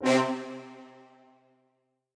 Brass (Domo 23).wav